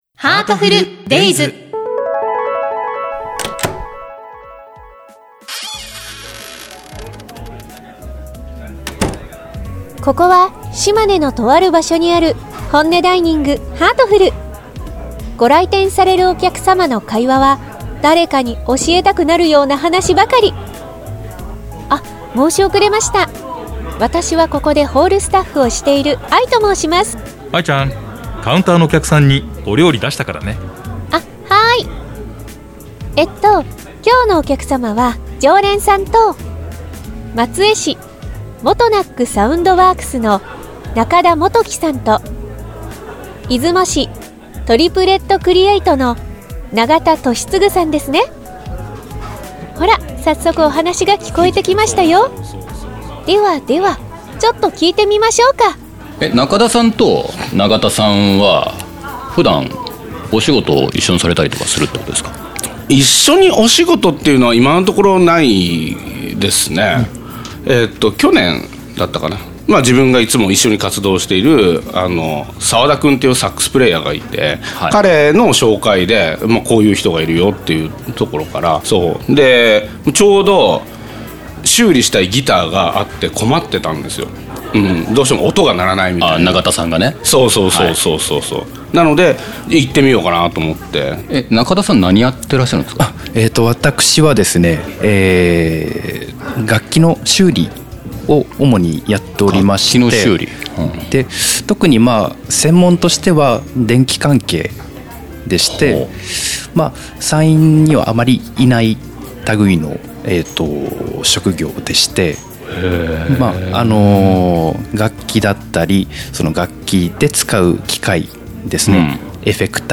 しまねで暮らす人たちのホンネが聴けるラジオ番組。